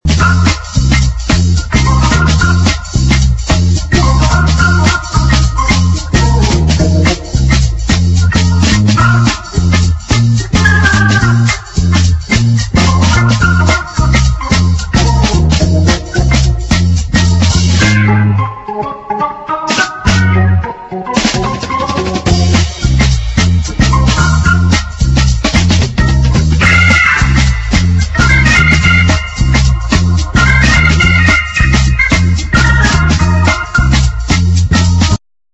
exciting medium instr.